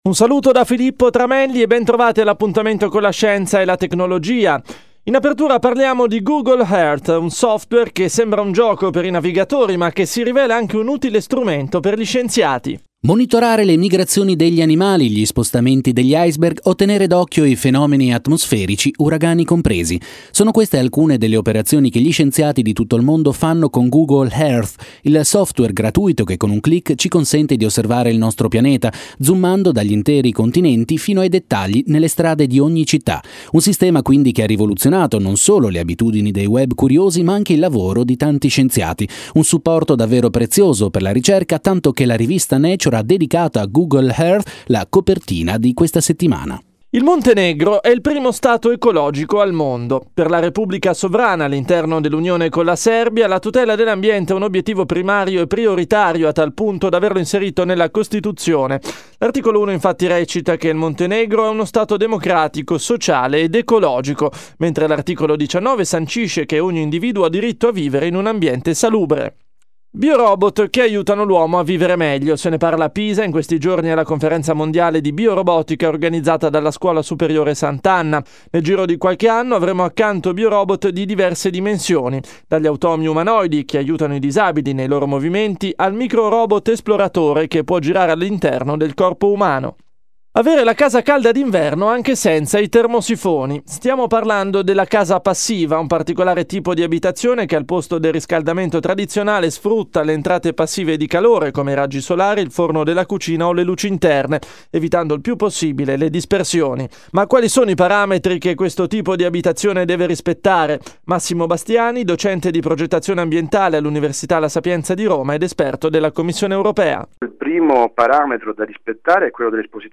CNR radio – AGR agenzia giornalistica, radio intervista